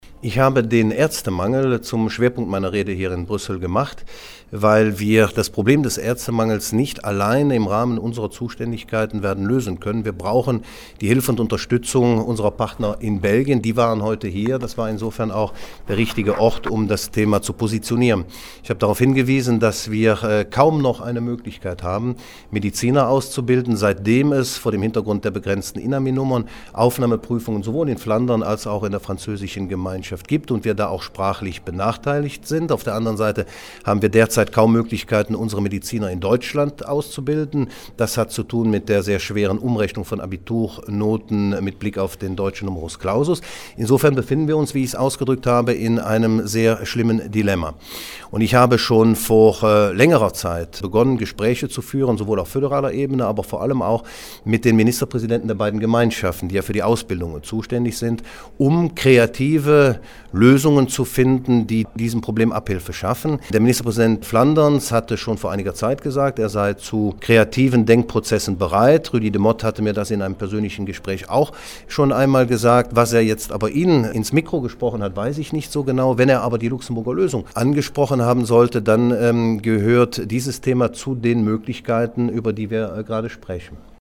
DG-Ministerpräsident Oliver Paasch nutzte den Empfang zum Tag der Deutschsprachigen Gemeinschaft in der Brüsseler Vertretung, um die anwesenden Vertreter der Föderal-, Gemeinschafts- und Regionalregierungen auf Probleme und Herausforderungen der Deutschsprachigen in Belgien hinzuweisen: